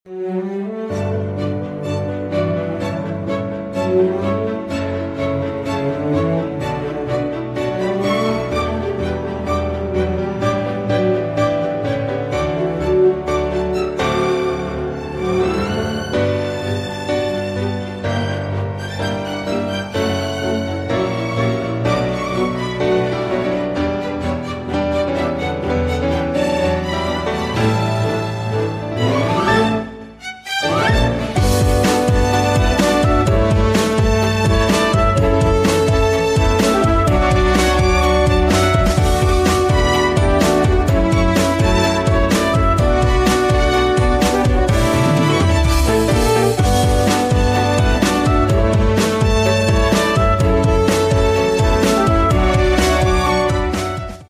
Plastic Profile Extrusion Line| Supermarket sound effects free download